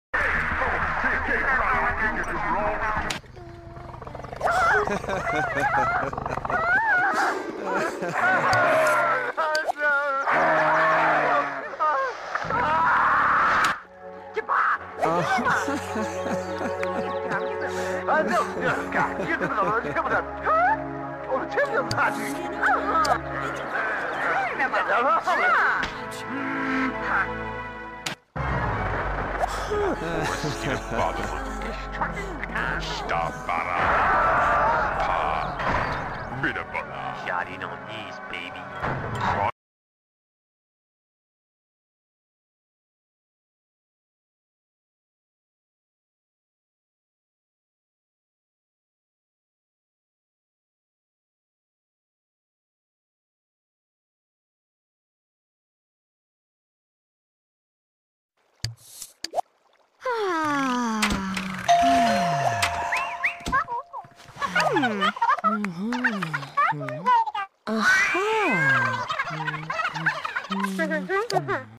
the sims 1 tv sounds sound effects free download